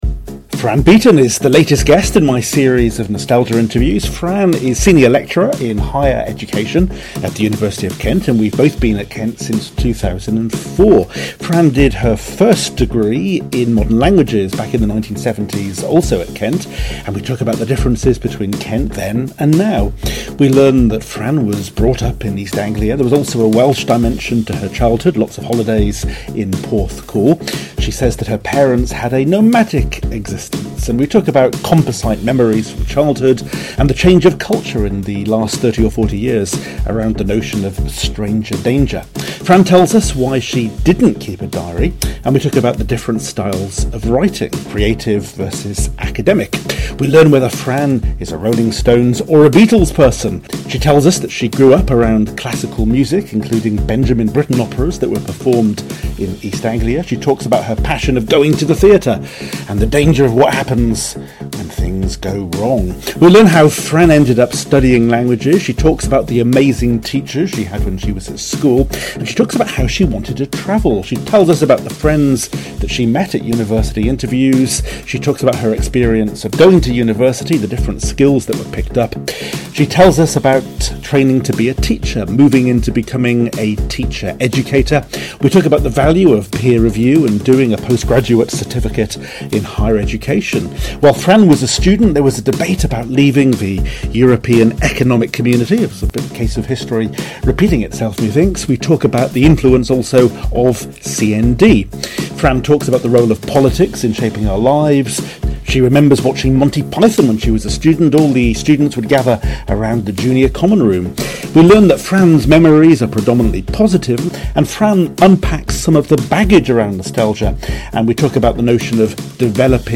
Nostalgia Interviews